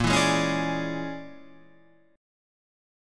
A#7#9b5 chord